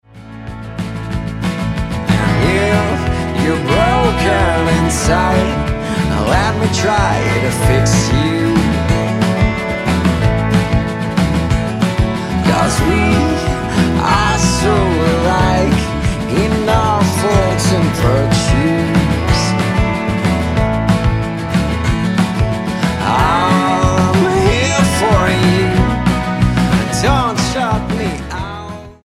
Style: Roots/Acoustic